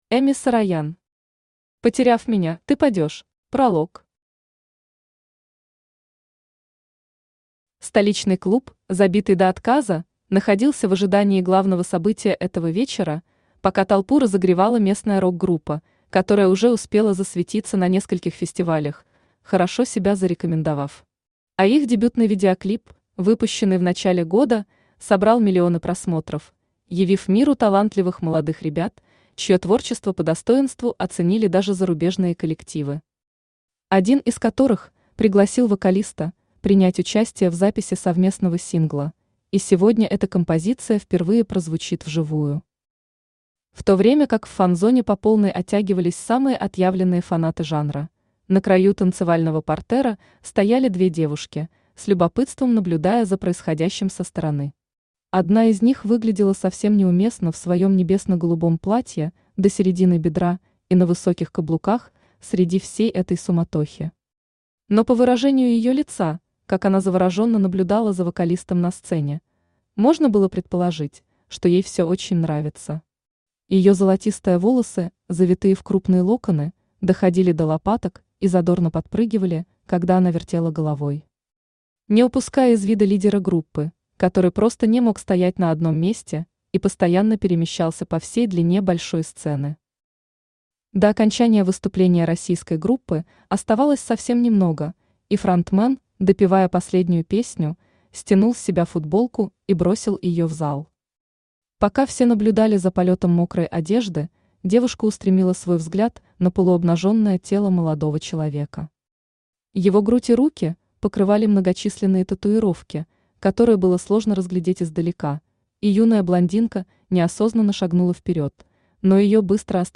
Аудиокнига Потеряв меня, ты падёшь | Библиотека аудиокниг
Aудиокнига Потеряв меня, ты падёшь Автор Эми Сароян Читает аудиокнигу Авточтец ЛитРес.